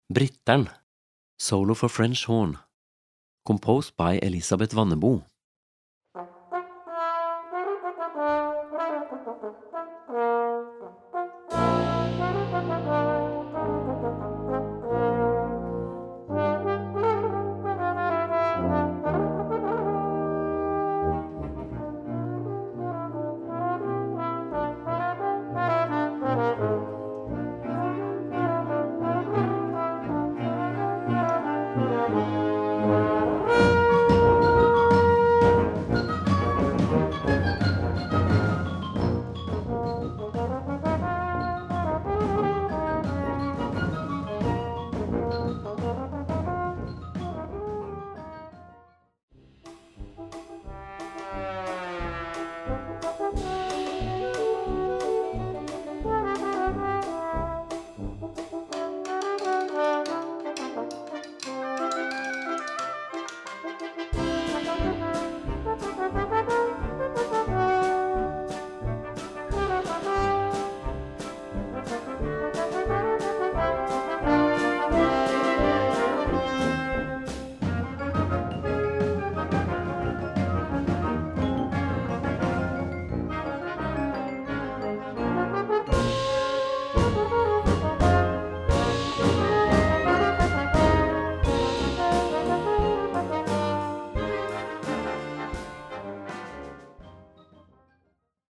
Gattung: Solo für Horn in F und Blasorchester
Besetzung: Blasorchester